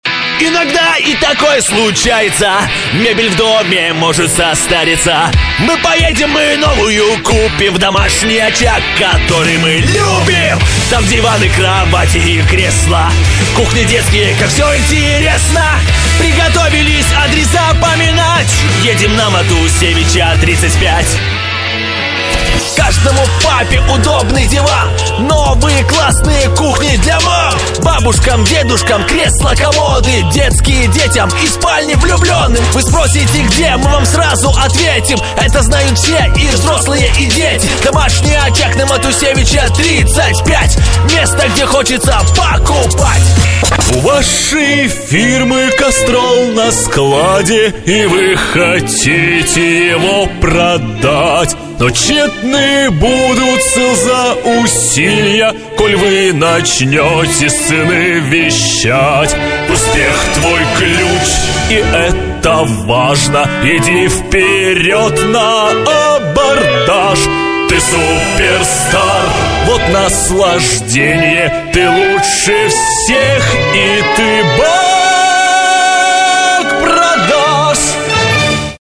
Молодой динамичный голос, но могу быть серьёзным :) График работы: Пн.
Тракт: микрофон: Neumann TLM 103,предусилитель: dbx 376,конвертор: RME Babyface Pro